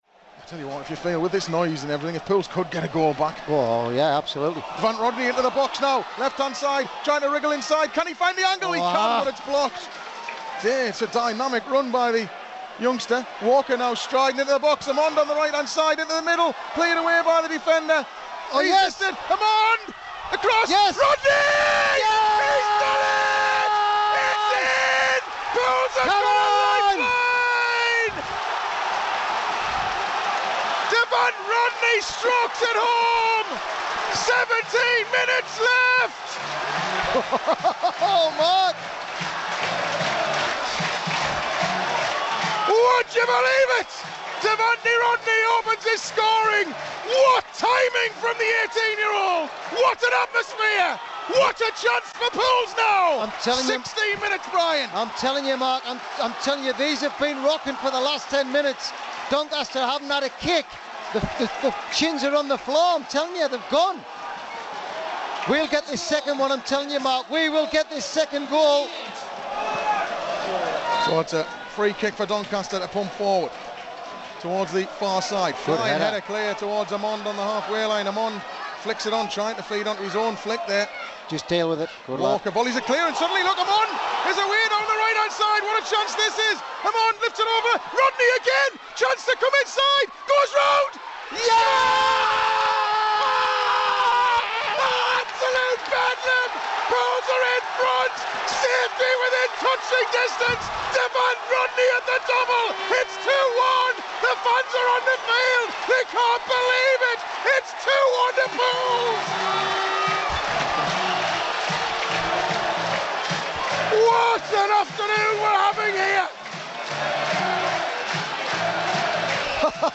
Listen back to the ecstasy and the agony from Pools PlayerHD's live coverage of Saturday's win over Doncaster Rovers.